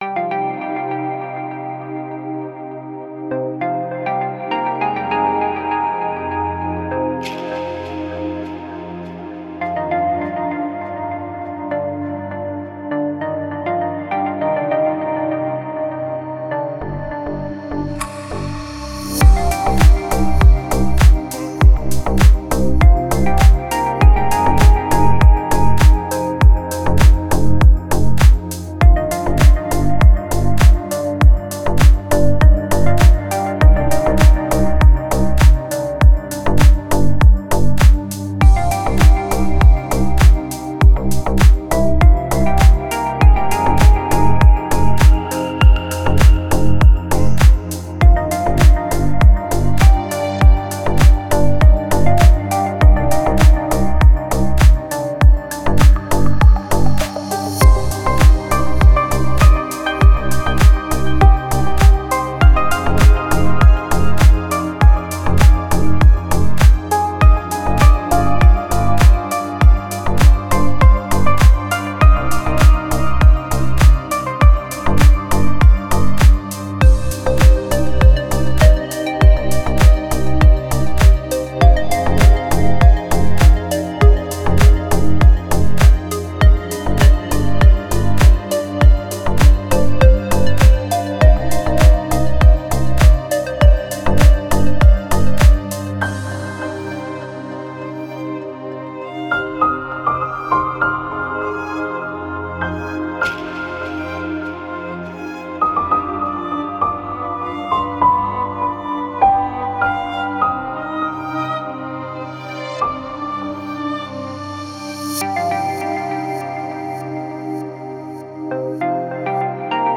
Спокойная музыка
спокойные треки